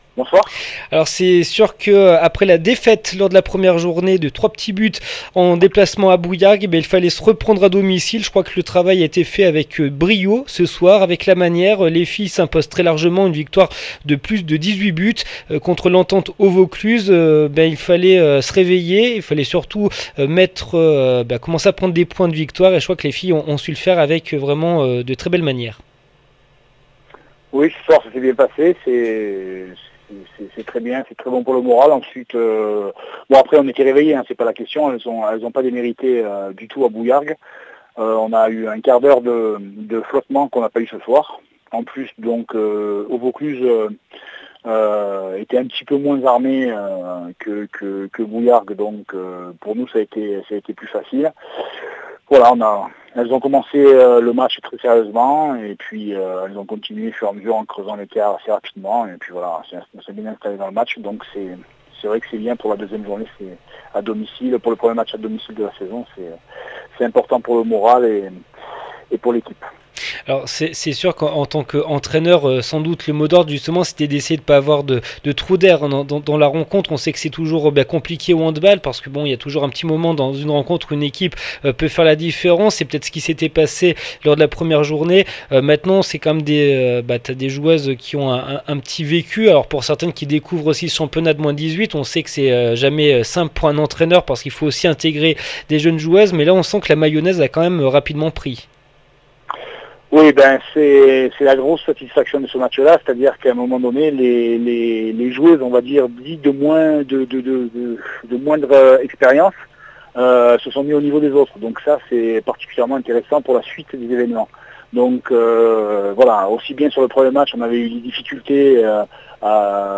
REACTION APRES MATCH